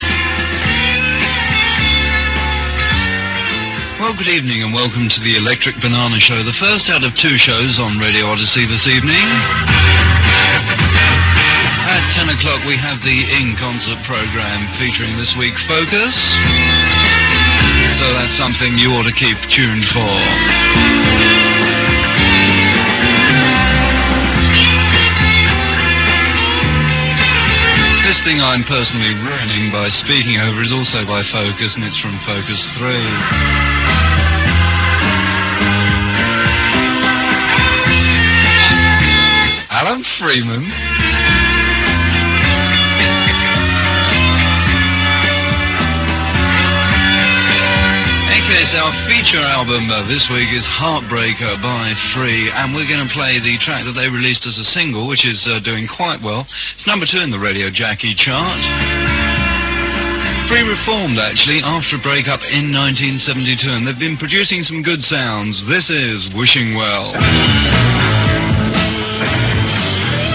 Heard in the London area via the London Transmitter of Independent Radio on 94.4FM, with a diet of progressive rock music and recorded concerts, little is known about this station but you can enjoy an off air recording of 'The Electric Banana Show' recorded off air in 1973.